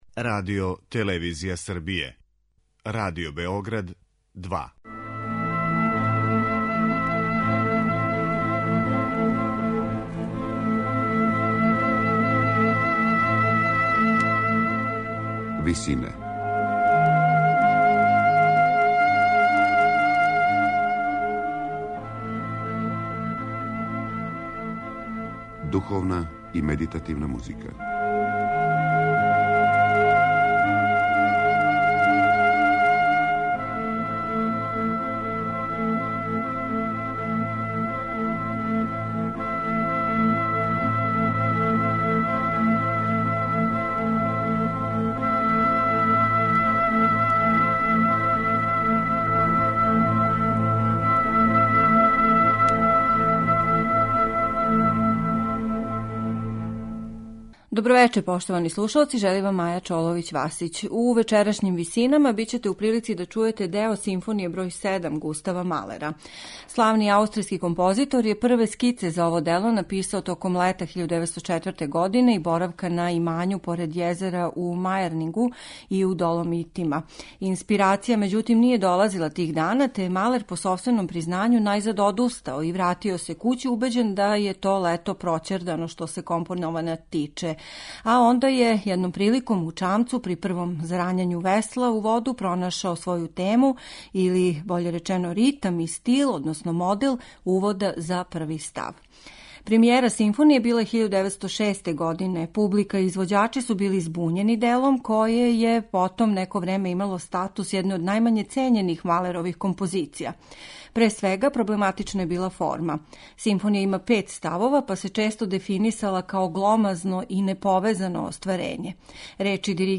То су два ноктурна која уоквирују тмурни скерцо и управо због њих се цела симфонија понекад назива Песма ноћи .